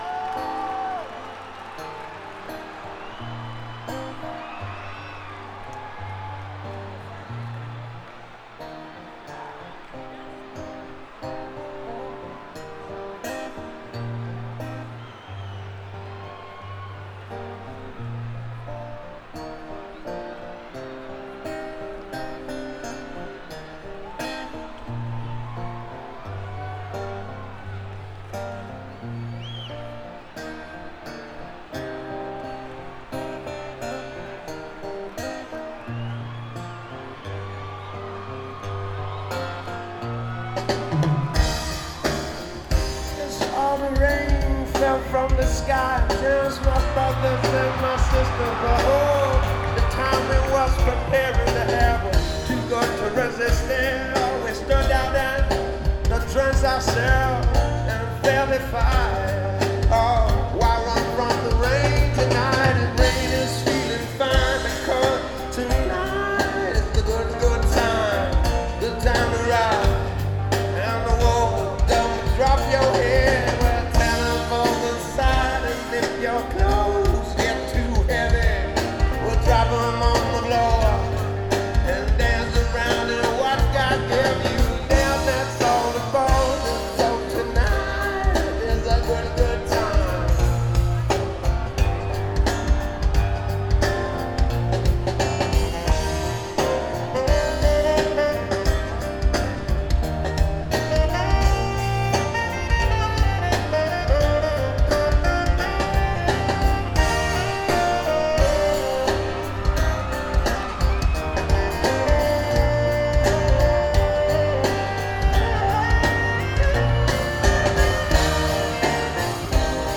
6.24.2004 Germain Amphitheater, Columbus, OH 8:00